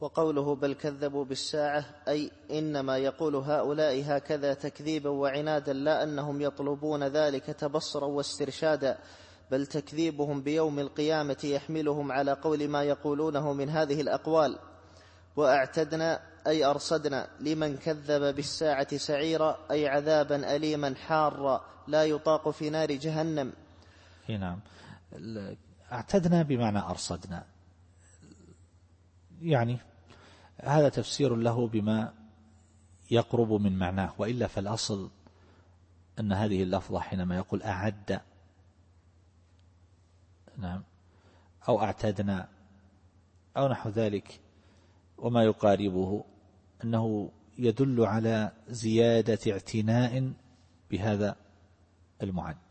التفسير الصوتي [الفرقان / 11]